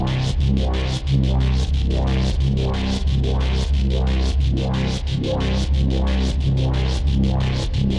Index of /musicradar/dystopian-drone-samples/Tempo Loops/90bpm
DD_TempoDroneE_90-C.wav